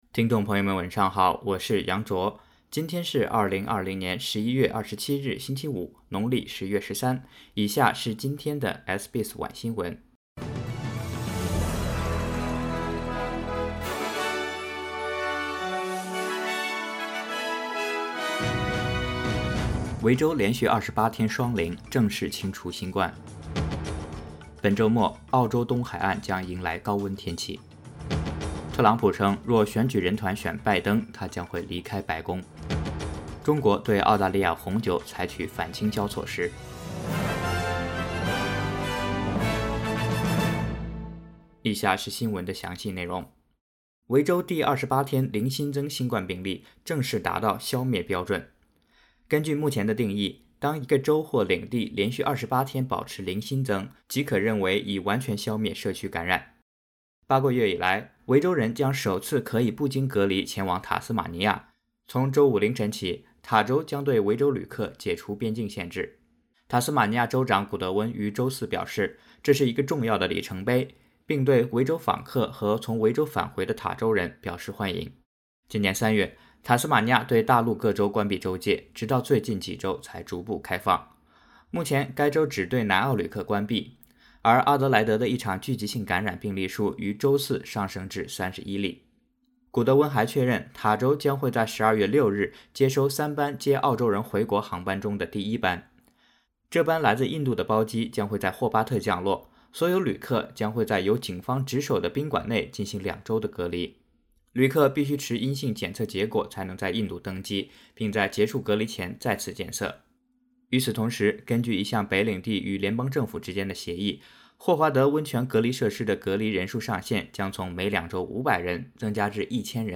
SBS晚新聞（11月27日）